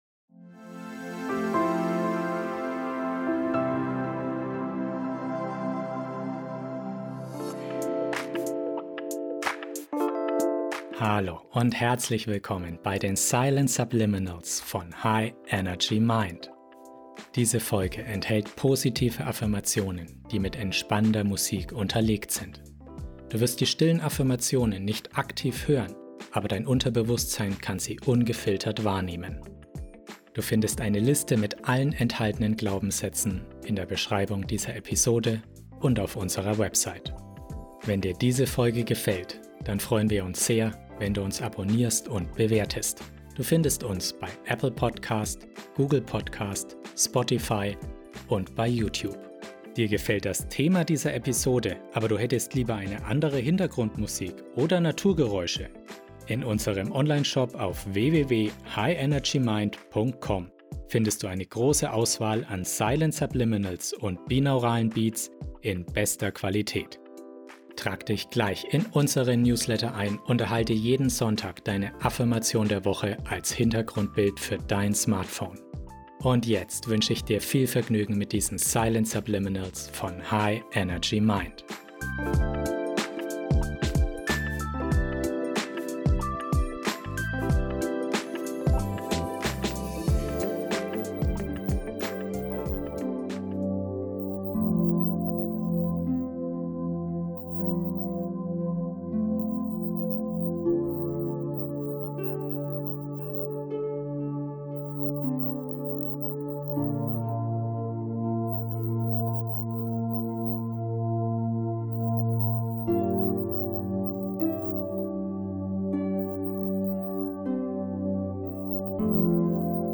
Beschreibung vor 5 Jahren Über diese Folge In dieser kraftvollen Folge im Silent Subliminals Podcast erfährst du, wie du mentale Stärke aufbauen kannst. Die beruhigende 432 Hz Musik begleitet kraftvolle Silent Subliminals, die dein Unterbewusstsein erreichen und deine mentale Widerstandskraft stärken können.
Silent Subliminals enthalten Botschaften, die in einer Frequenz abgespielt werden, die oberhalb der Hörschwelle des menschlichen Ohres liegt.